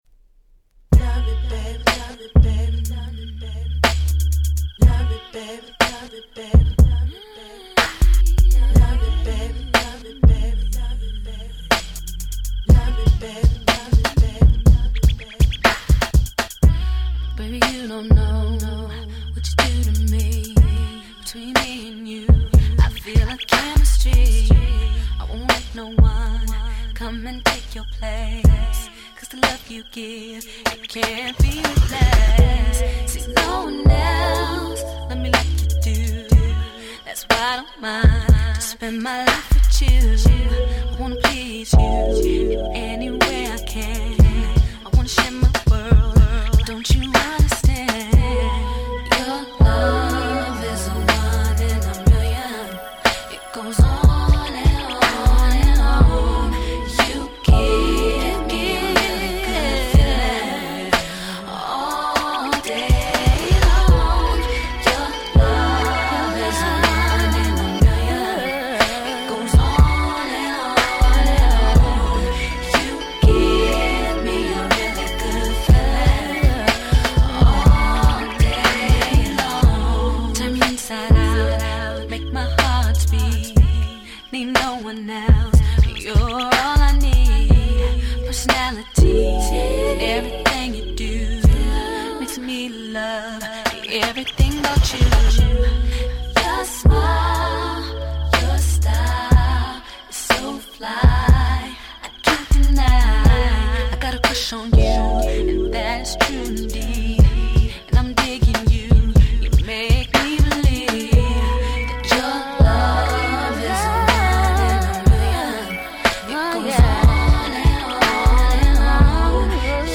96' Big Hit R&B/Slow Jam !!